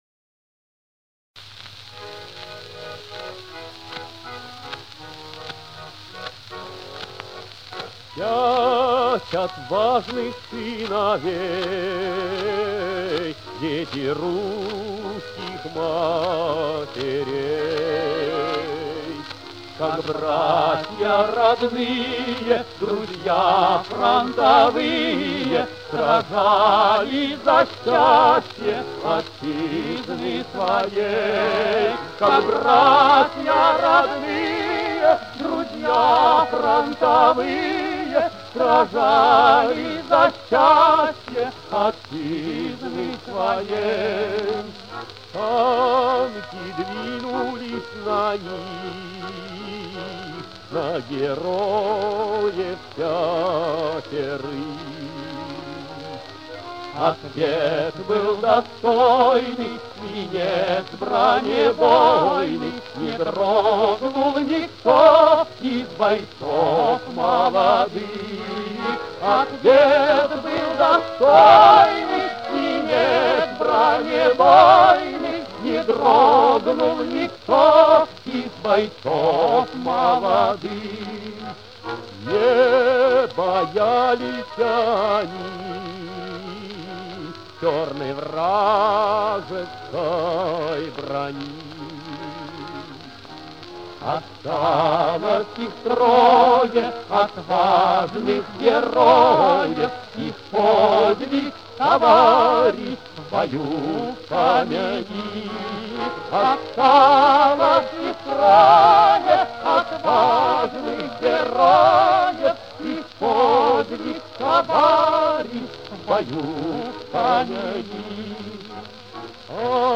Описание: УЛУЧШЕНИЕ качества.